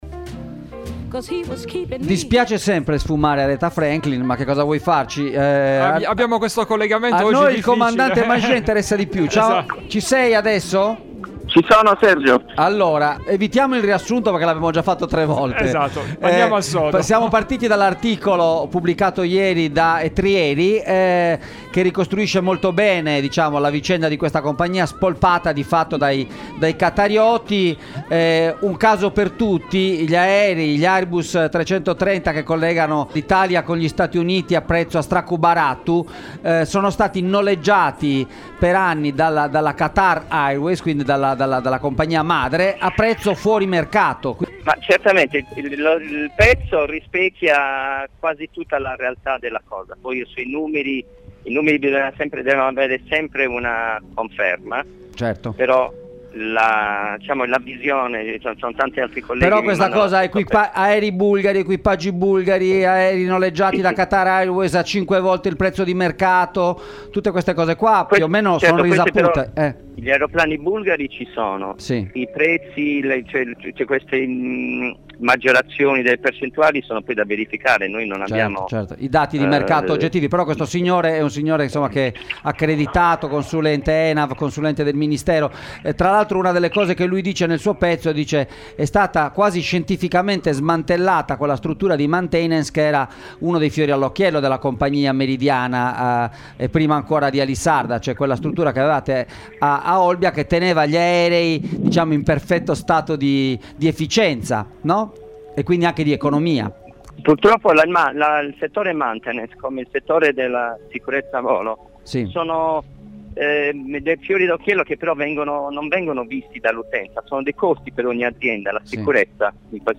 Air Italy, una compagnia “svuotata” dall’interno: intervista